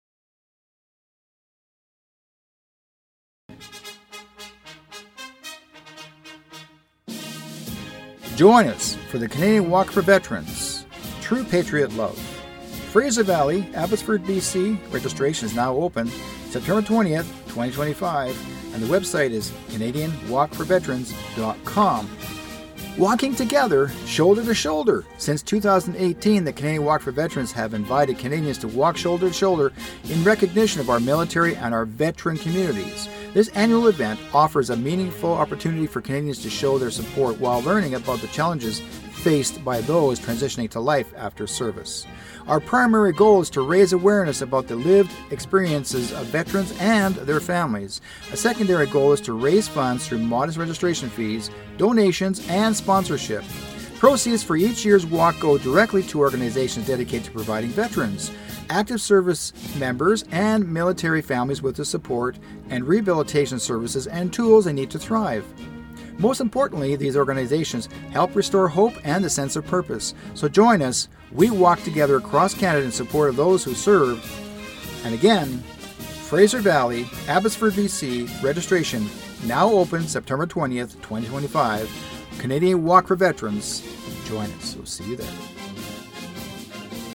CFRO 100.5FM: Canadian Walk For Veterans PSA